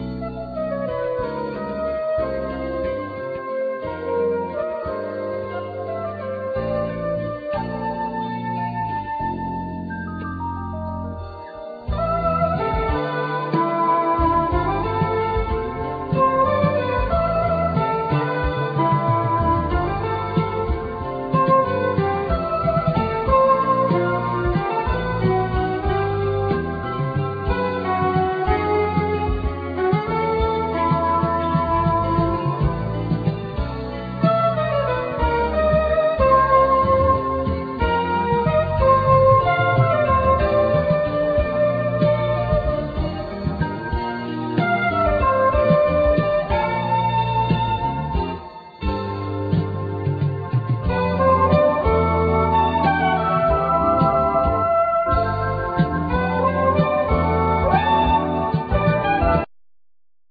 Alto+Soprano sax,Synthesizer
Keyboards
Piano
Tenor+Soprano sax.Violin
Guitar
Bass,Byan
Drums
Percussions